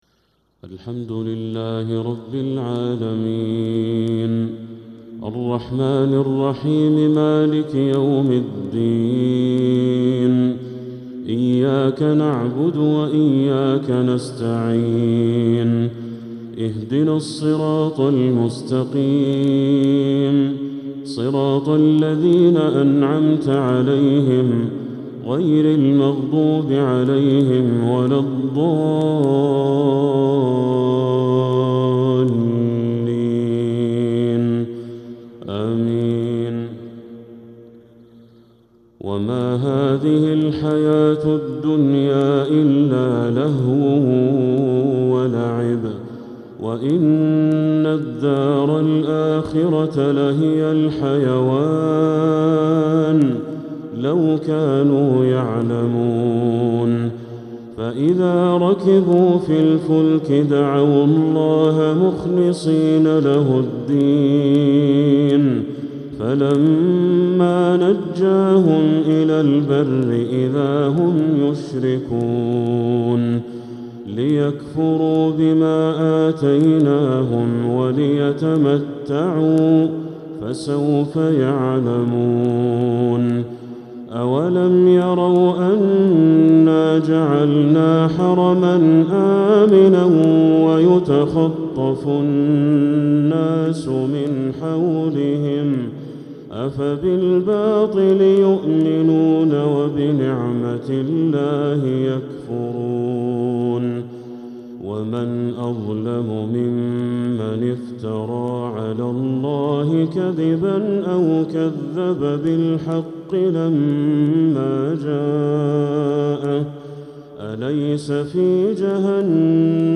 عشاء السبت 17 محرم 1447هـ | خواتيم سورتي العنكبوت 64-69 و الروم 55-60 | Isha prayer from surah Al-Ankabut and Ar-Room 12/7/2025 > 1447 🕋 > الفروض - تلاوات الحرمين